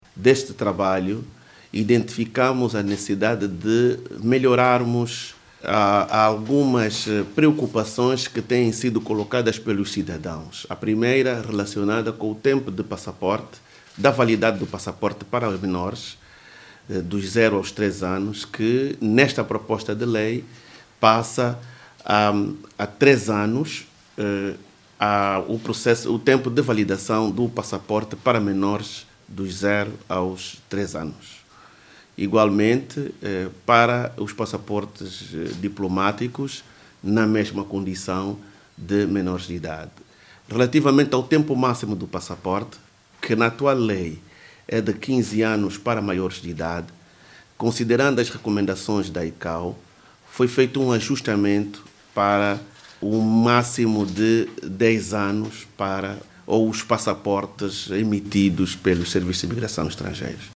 Na ocasião, o Ministro do Interior, Manuel Homem, adiantou que os passaportes ordinários, de serviço e diplomáticos vão passar a ter validade de até dez anos.
MANUEL-HOMEM-1-6HRS.mp3